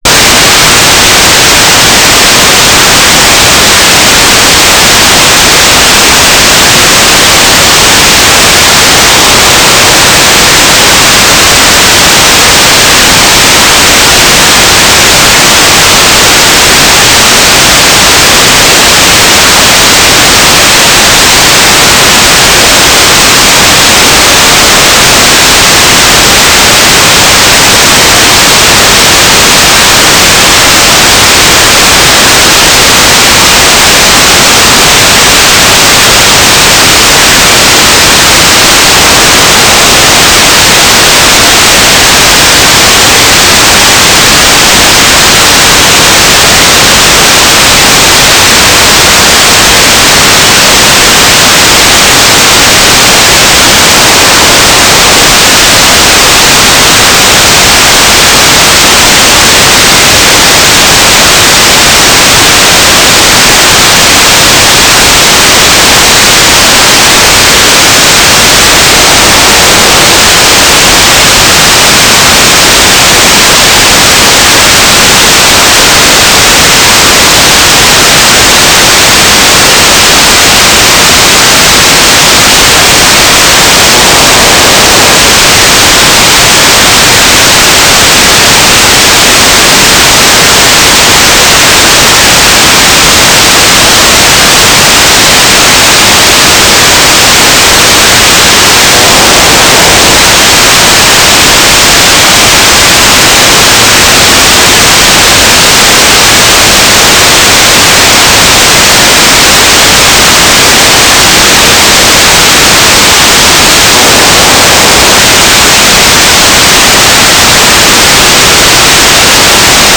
"transmitter_mode": "FSK",